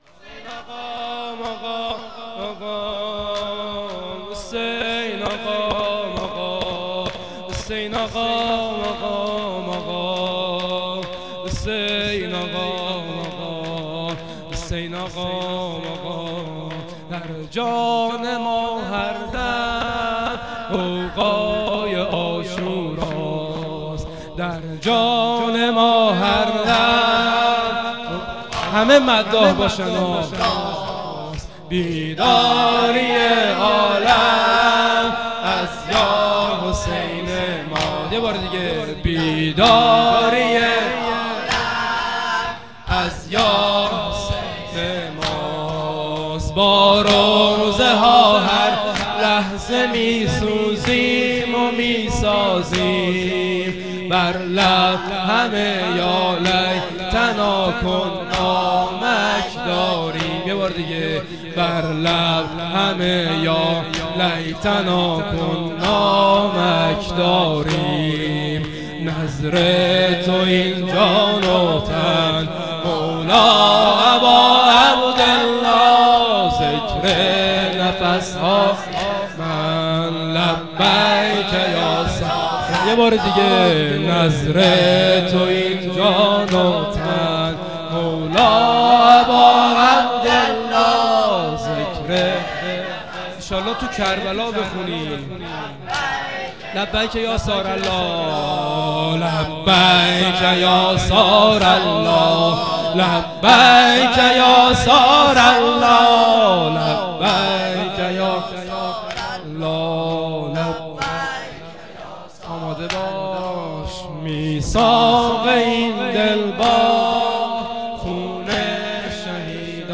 خیمه گاه - هیئت قتیل العبرات - سرود همگانی